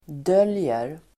Uttal: [d'öl:jer]